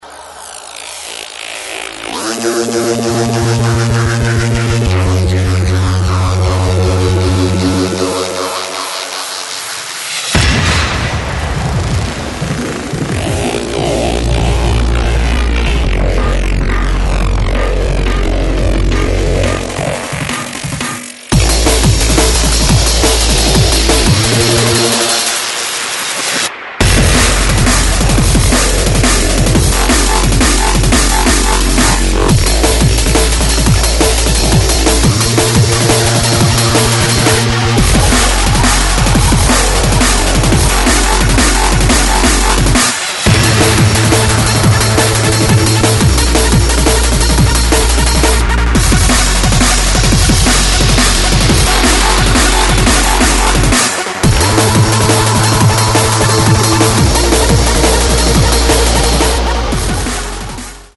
Styl: Drum'n'bass, Hardtek/Hardcore